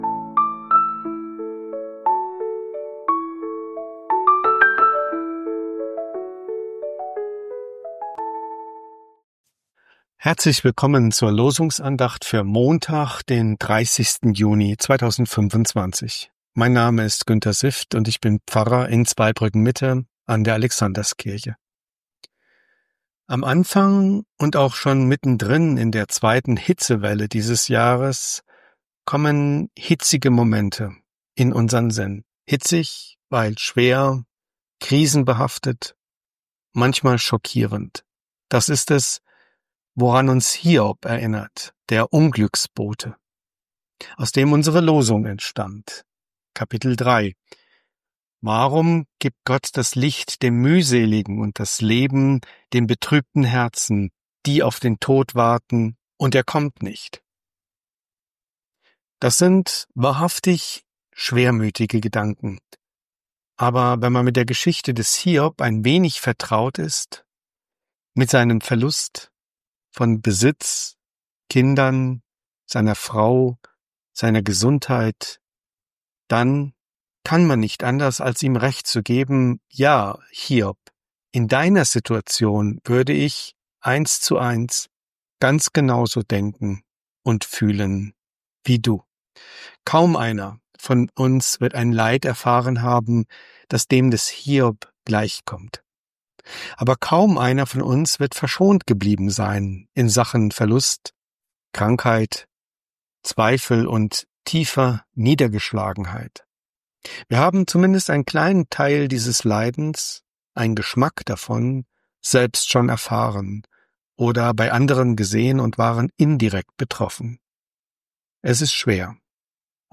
Losungsandacht für Montag, 30.06.2025